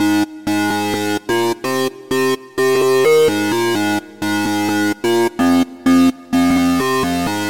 众议院合成器铅字分层
Tag: 128 bpm House Loops Synth Loops 1.89 MB wav Key : D